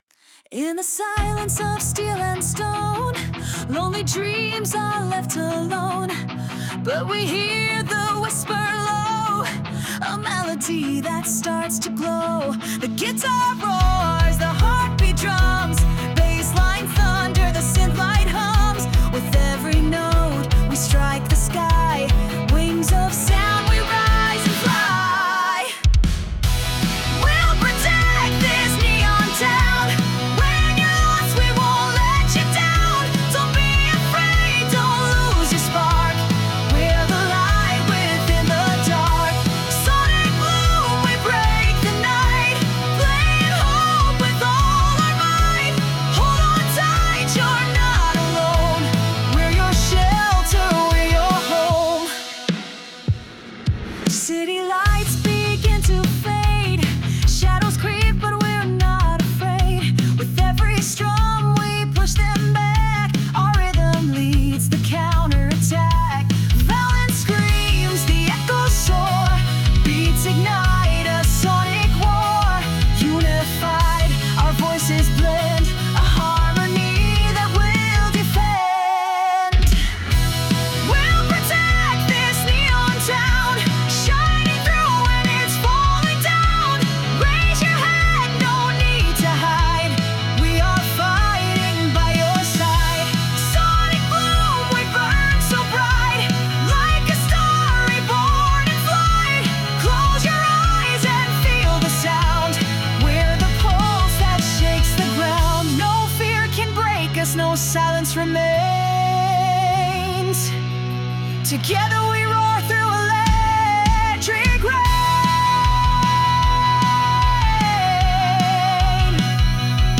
公開している音楽は、映画やアニメ、ゲームに想定したBGMや、作業用のBGMを意識して制作しています。